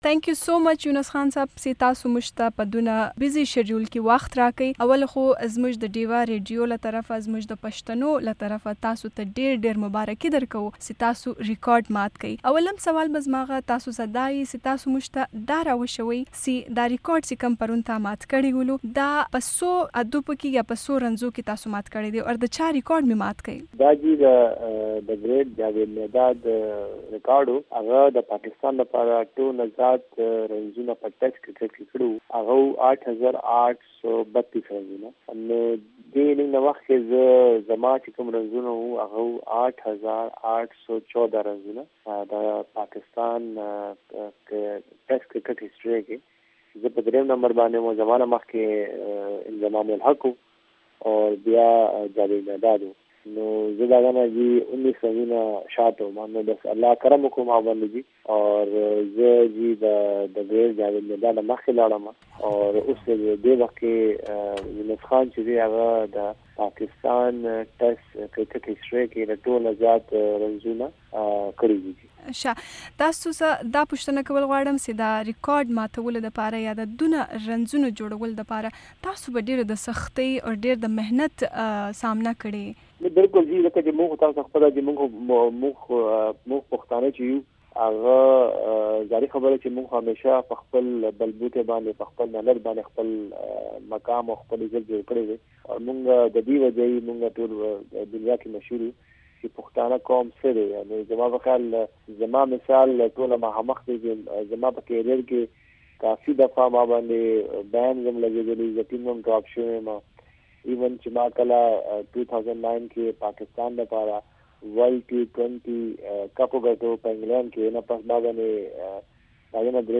د پاکستان کرکټ ټیم پخوانی کپتان او بیټسمین یونس خان چې په ټیسټ کړکټ کې يي پاکستان لخوا تر ټولو زیاتو رنزونو ریکارډ قائم کړی دی وي او ای ډیوه ته خصوصي مرکه کې وايي پښتانه به یوازې په خپل محنت ځان ته ځائې پیدا کوي گني نو د مشکلاتو سره به مخ کیږي.